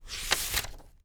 TURN PAGE2-S.WAV